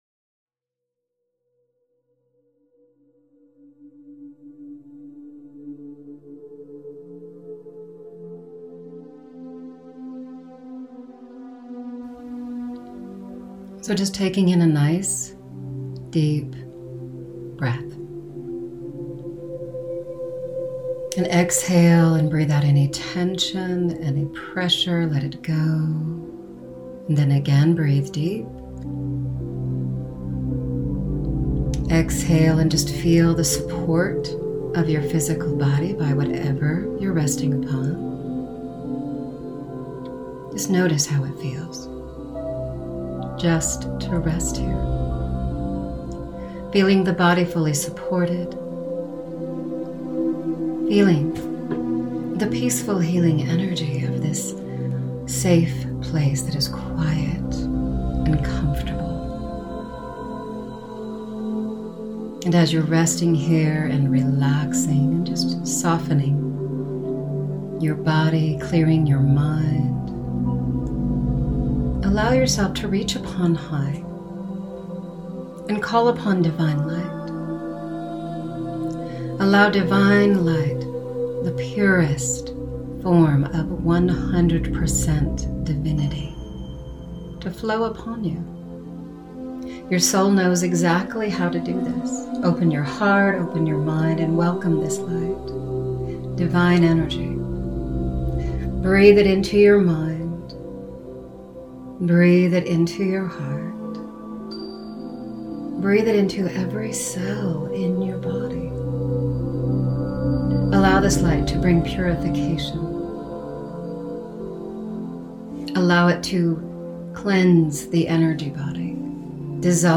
Guided Meditation in a soothing voice. Energy healing, energy clearing, Divine Light, meditation, spirituality.